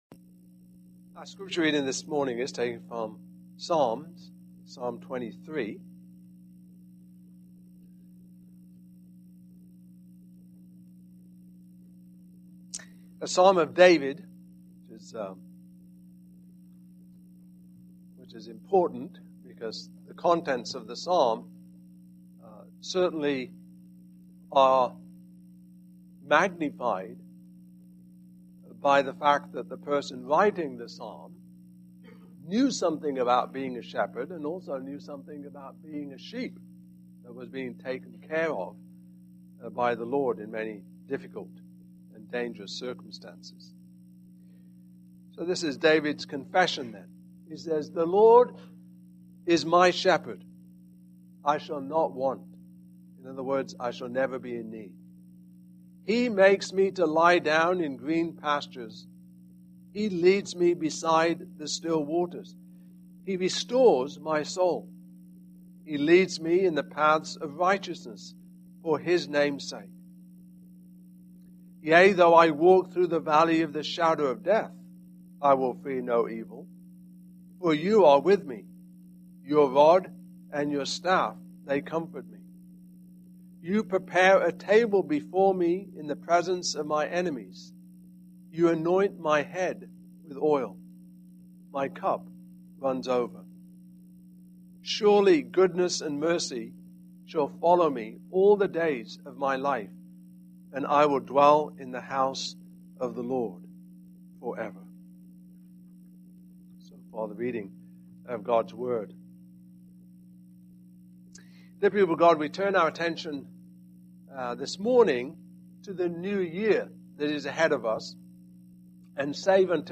New Year's Eve Sermon
Service Type: Morning Service